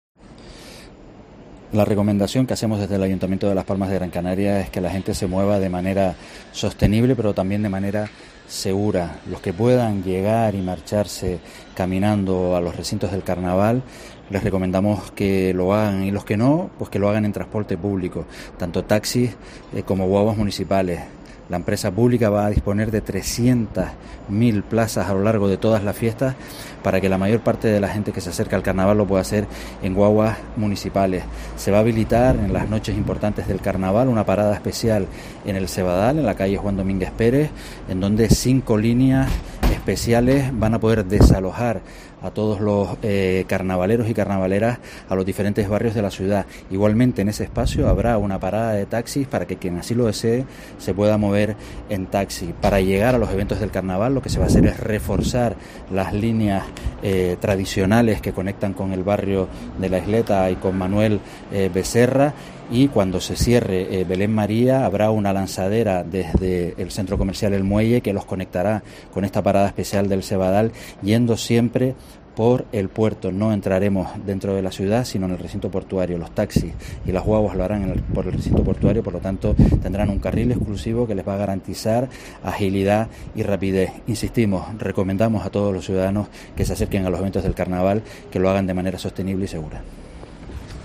José Eduardo Ramírez, concejal de movilidad del ayuntamiento de Las Palmas de Gran Canaria